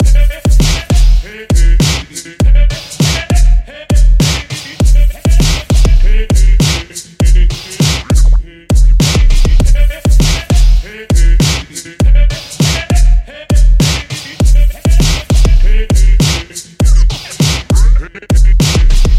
嘿嘿鼓 100
描述：速度：100bpm 只是瞎折腾。
Tag: 100 bpm Hip Hop Loops Drum Loops 3.24 MB wav Key : Unknown